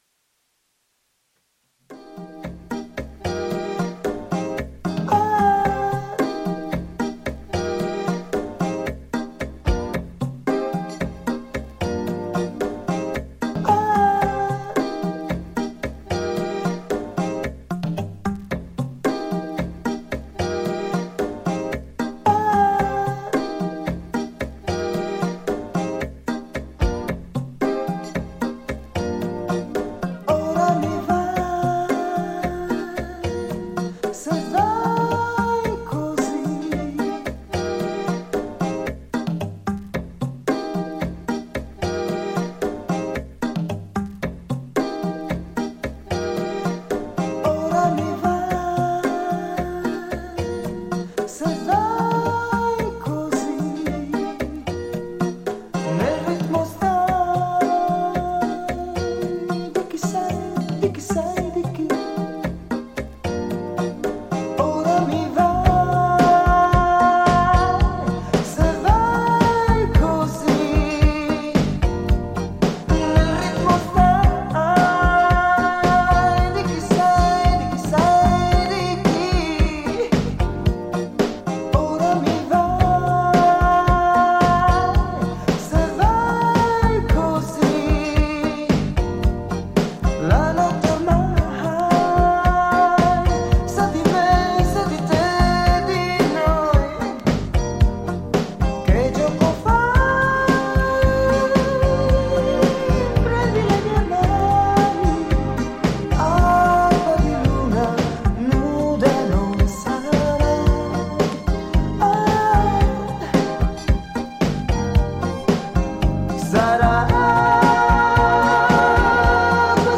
ジャンル(スタイル) DISCO / NU DISCO / RE-EDIT